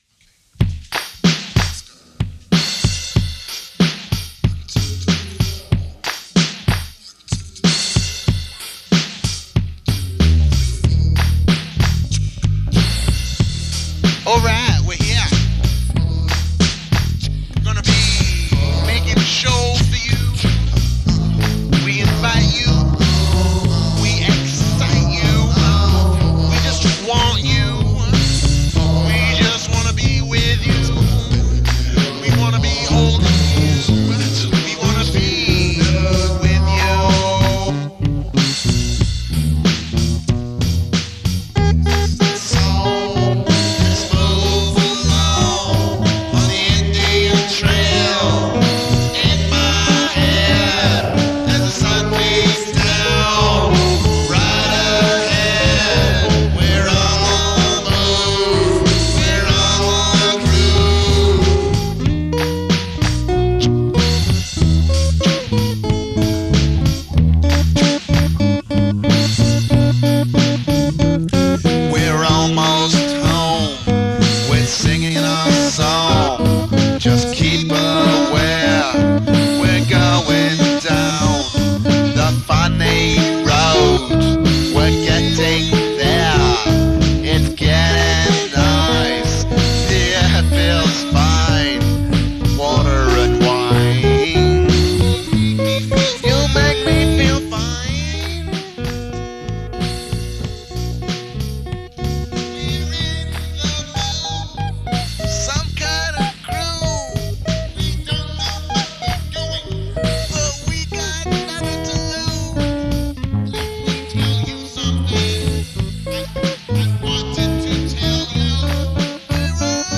WEIRD 4-TRACK EXPERIMENTS (1988-1991)
vocals, guitar
bass